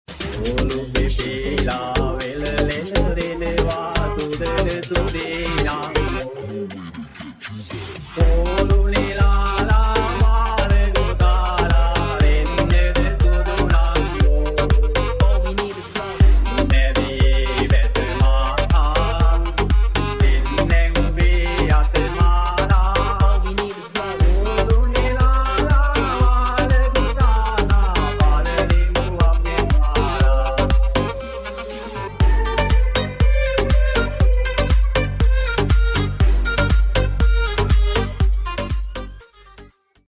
EDM Remix New Song
EDM Remix